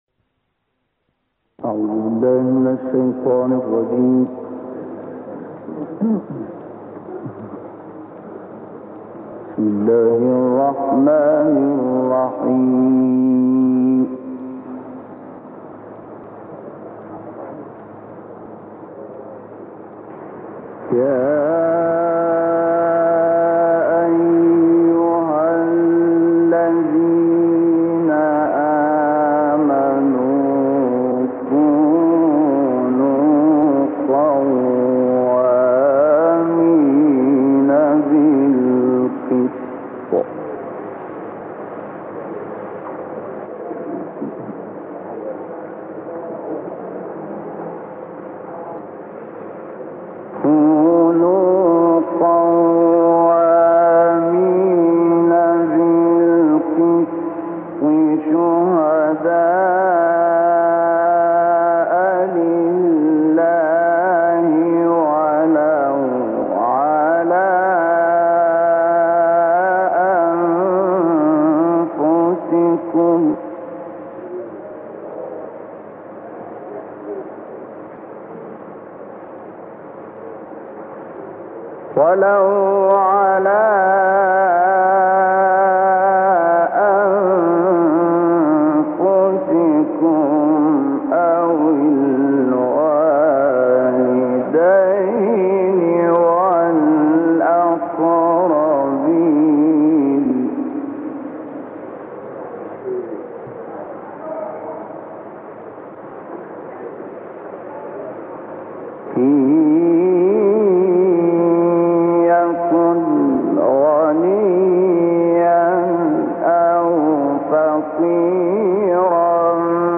تلاوت های ماندگار | سوره نساء آیات 135 تا 147- استاد شحات محمد انور
سوره نساء آیات 135 تا 147 با صدای دلنشین و ماندگار مرحوم استاد محمد شحات انور از قاریان بزرگ قرآن در مصر به مدت 18 دقیقه به همراه متن و ترجمه آیات